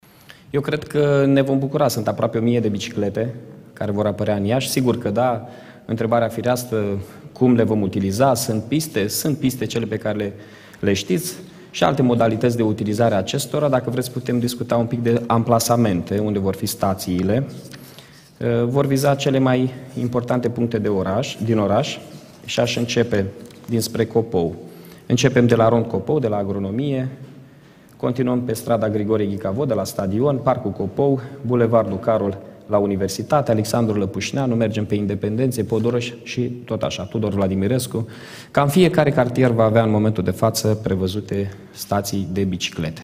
Unde vor fi stații de biciclete? Primarul municipiului Iași, Mihai Chirica